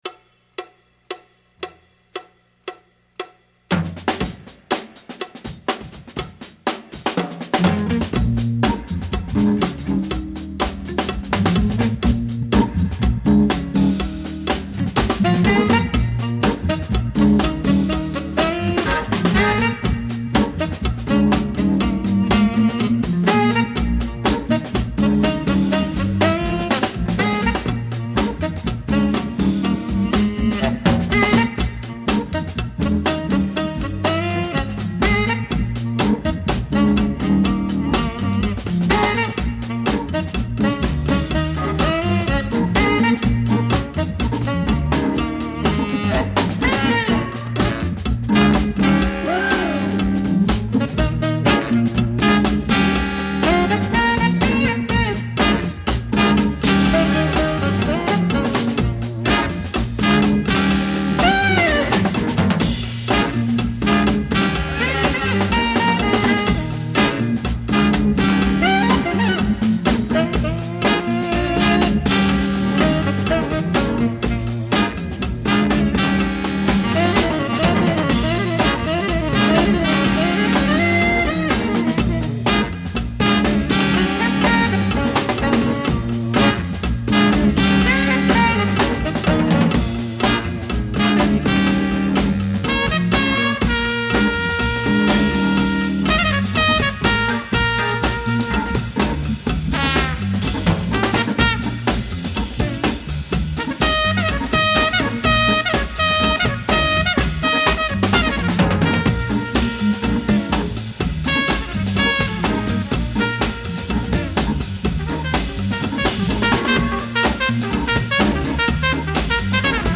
Heaven's Rock & Roll Band just got funkier.